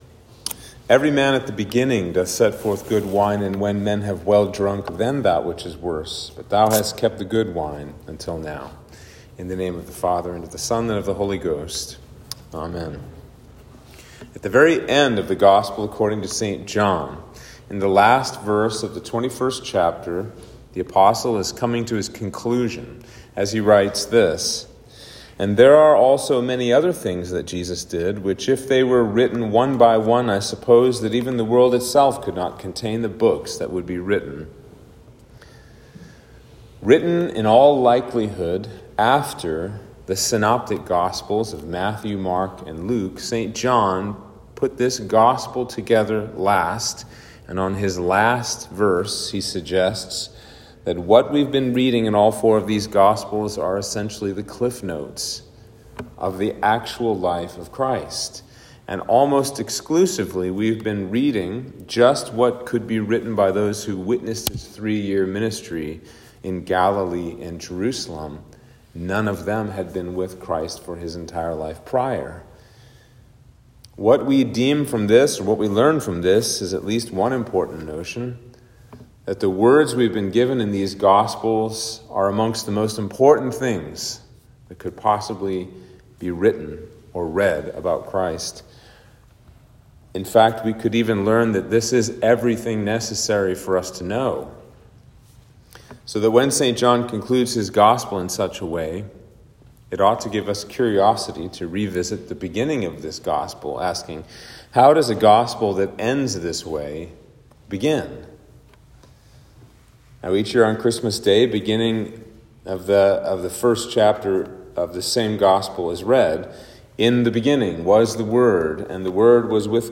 Sermon for Epiphany 3